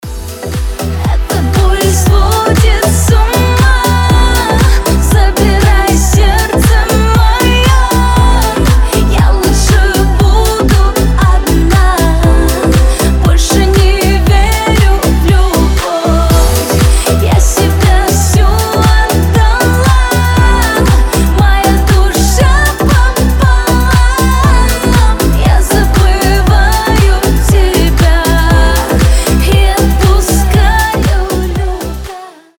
• Качество: 320, Stereo
громкие
deep house
грустные
Club House